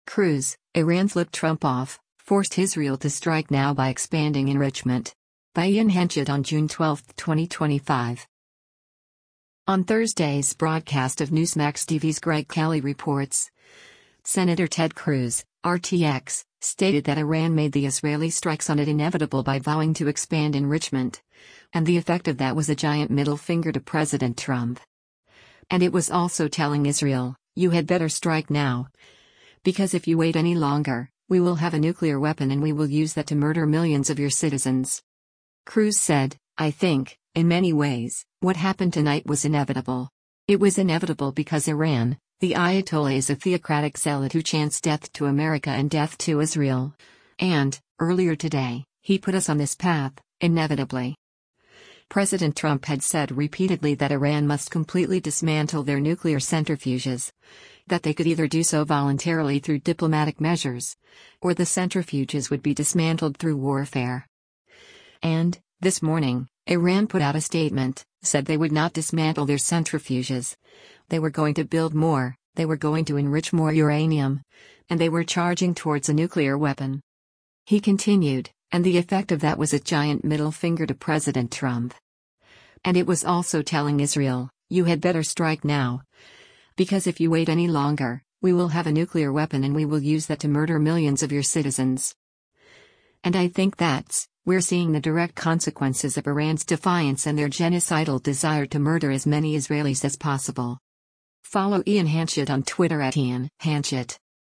On Thursday’s broadcast of Newsmax TV’s “Greg Kelly Reports,” Sen. Ted Cruz (R-TX) stated that Iran made the Israeli strikes on it “inevitable” by vowing to expand enrichment, “And the effect of that was a giant middle finger to President Trump. And it was also telling Israel, you had better strike now, because if you wait any longer, we will have a nuclear weapon and we will use that to murder millions of your citizens.”